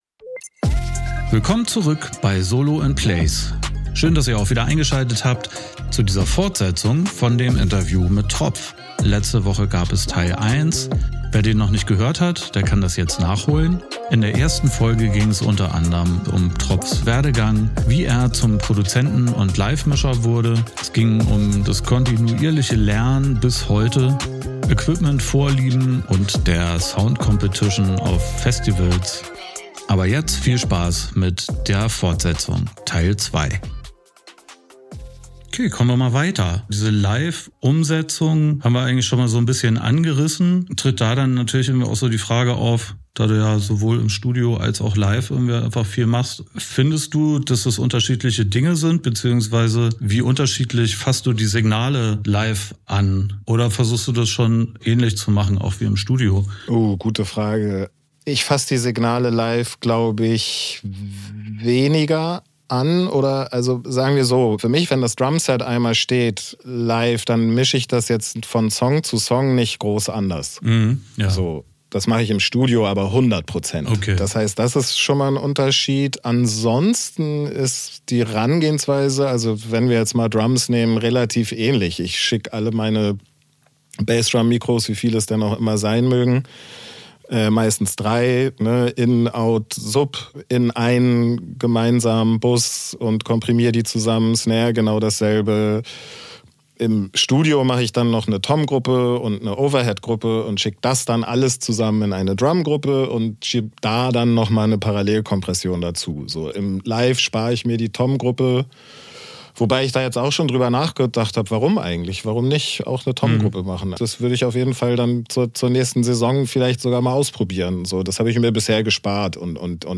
Unterhaltung